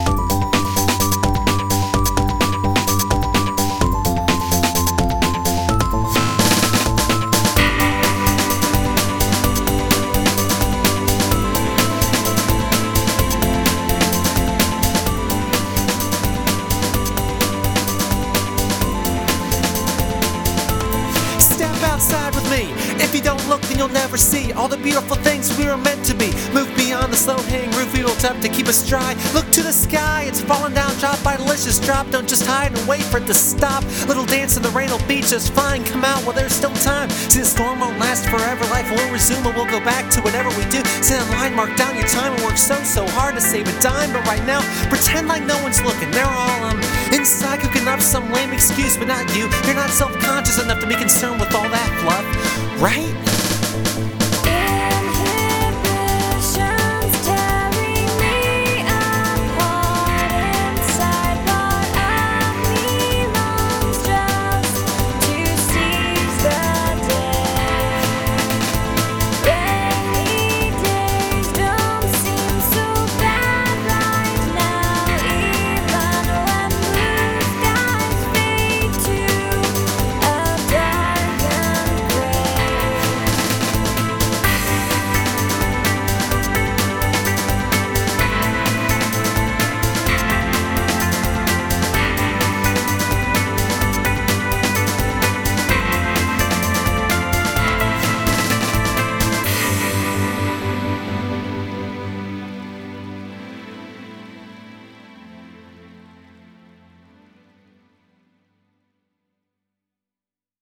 128BPM